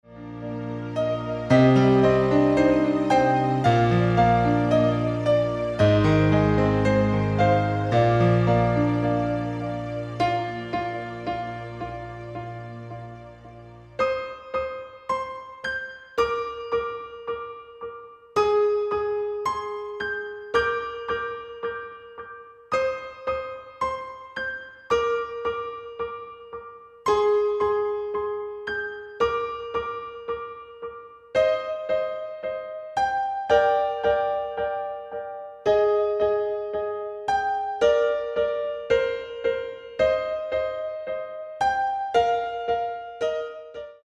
Taal uitvoering: Instrumentaal
Genre: Easy listening
- GM = General Midi level 1
Demo's zijn eigen opnames van onze digitale arrangementen.